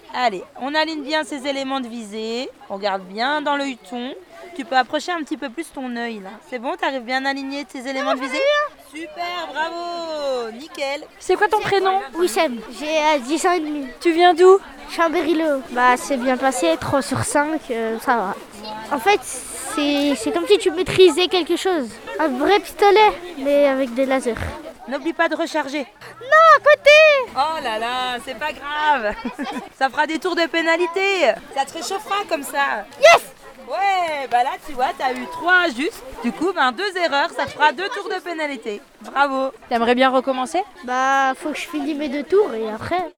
ITC Tout sonore 2-Découverte métiers montagne Grand-Bo.wav